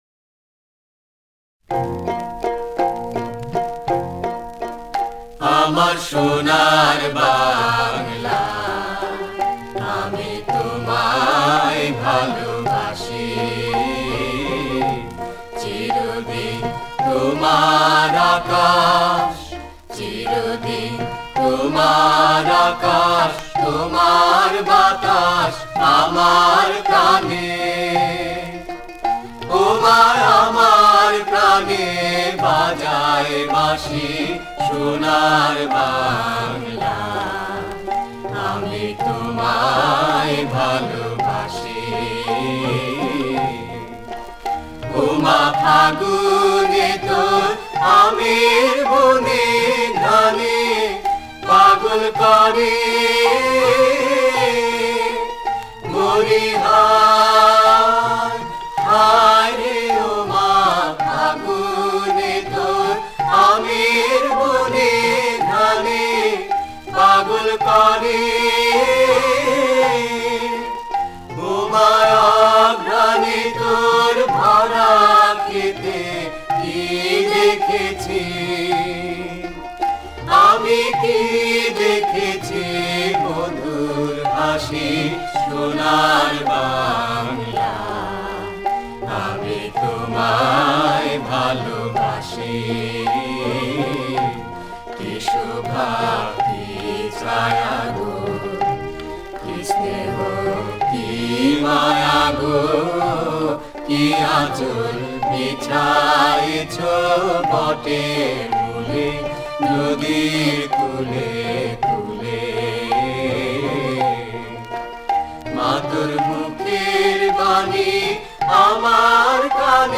ছায়ানট সঙ্গীত বিদ্যায়তন কর্তৃক পরিবেশিত জাতীয় সঙ্গীতের শ্রবণ নমুনা
বাউল গানের সুরে এর সুর নিবদ্ধ।
গানটি ৩।৩ মাত্রা ছন্দে  'দাদরা' তালে নিবন্ধ।
লয়-মধ্য।